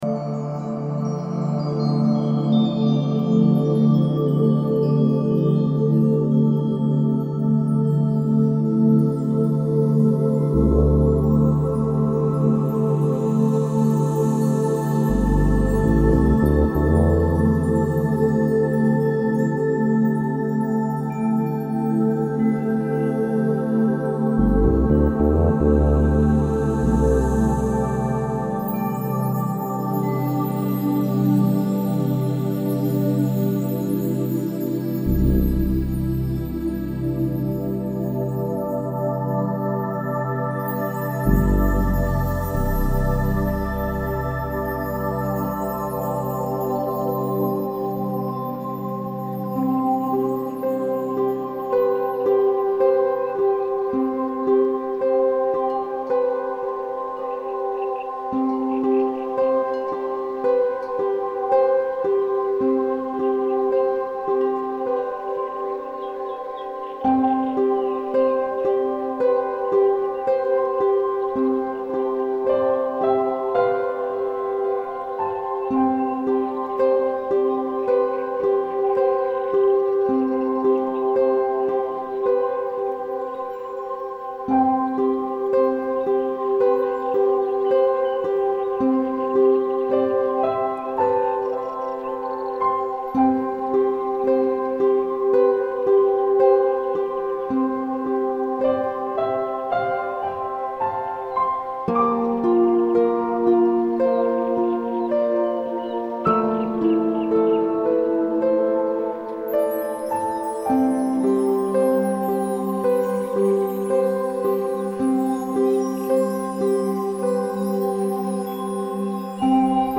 Erlebnismeditation mit dem Schutzengel und der Farbe Weiß
Eine kleine, aus weißem Licht geborene Elfe ist unsere Wegweiserin in das Reich der lichtvollen Begleiter, in dem wir unserem persönlichen Schutzengel begegnen dürfen. Diese CD voller Herzenswärme und Inspiration enthält eine gesprochene Erlebnismeditation sowie eine rein instrumentale Klangpoesie. Die ermutigenden Worte und die sanften Klänge führen zu völliger Entspannung und lassen uns die Kraft des Regenbogens spüren.